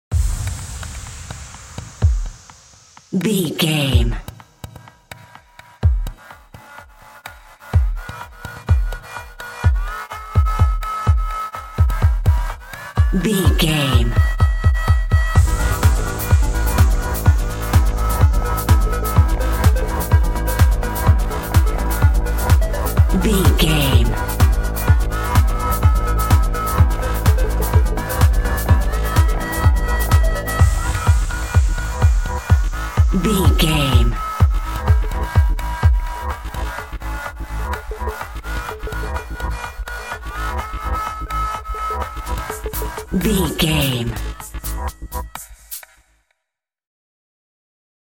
Top 40 Electronic Dance Music 30 Sec.
In-crescendo
Aeolian/Minor
G#
Fast
energetic
uplifting
hypnotic
groovy
drum machine
synthesiser
house
techno
trance
synth leads
synth bass
upbeat